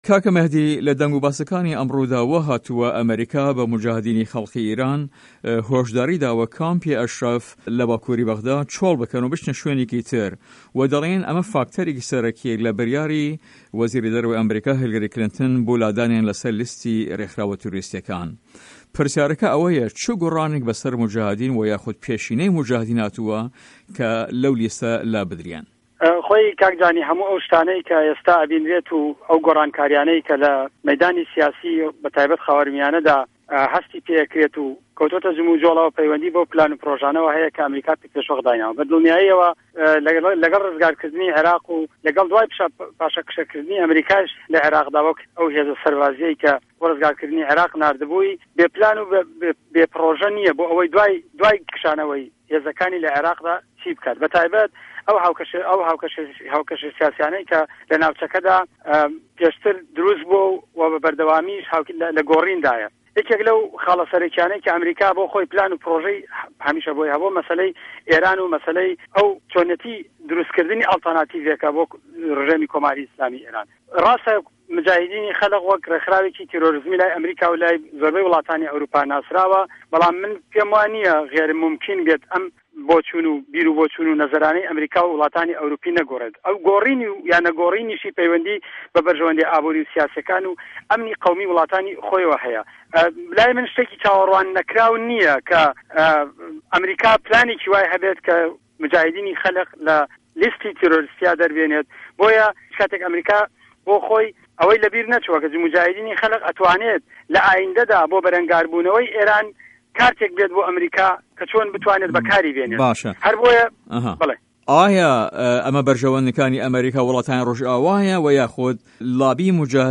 وتو وێژ